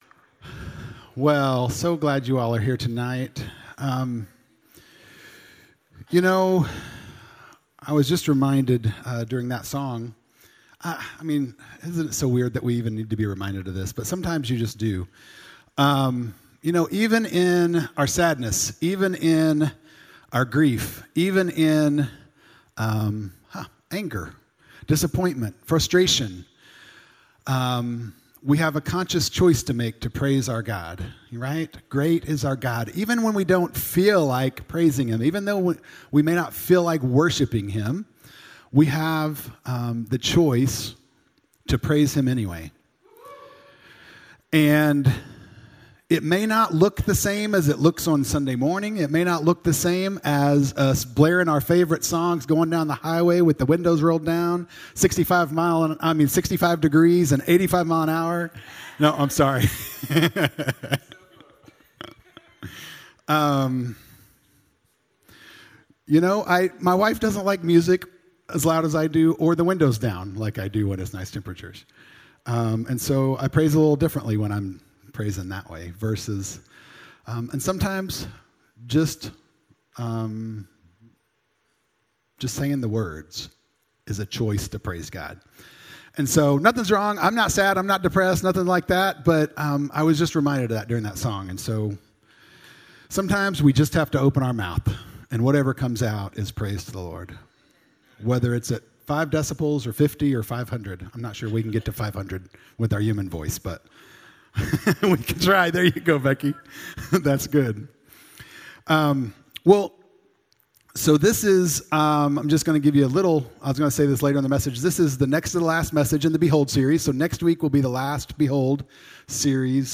Sermons | Grace Pointe Church